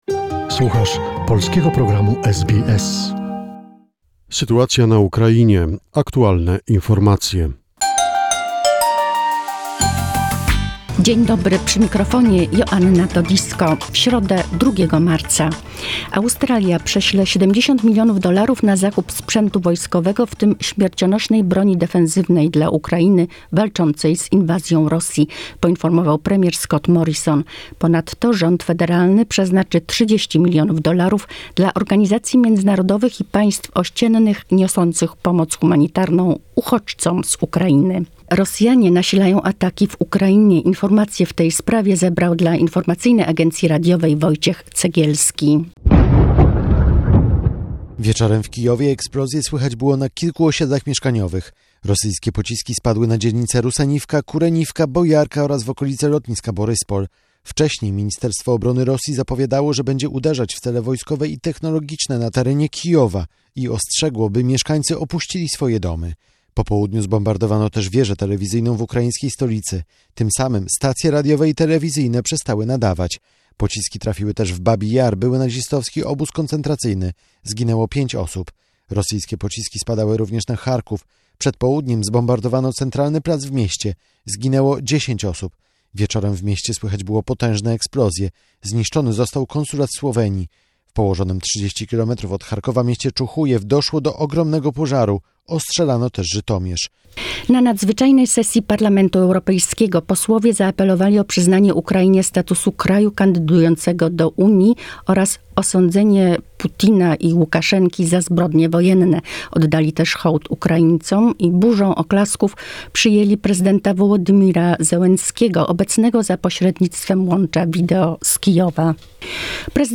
The most recent information and events about the situation in Ukraine, a short report prepared by SBS Polish.